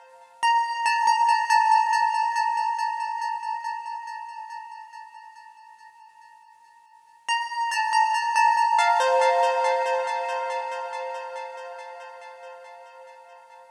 VTS1 Devastating Truth Kit 140BPM Soft Pluck Intro WET.wav